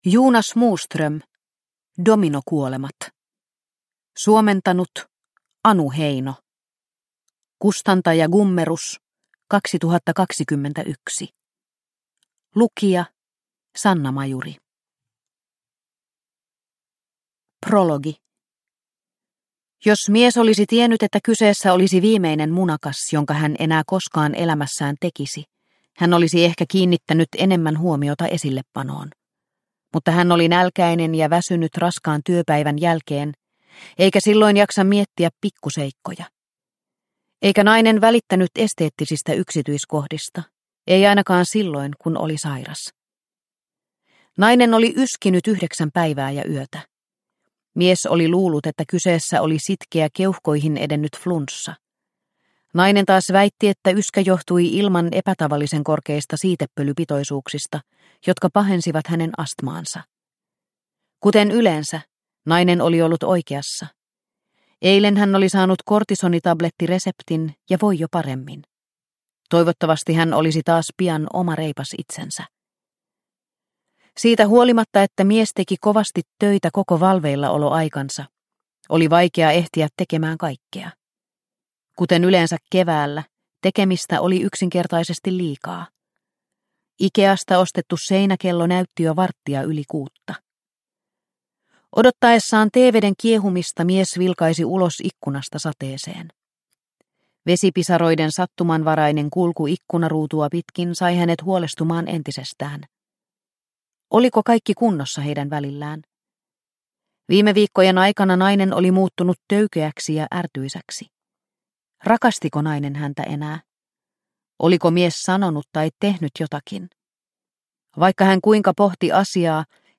Dominokuolemat – Ljudbok – Laddas ner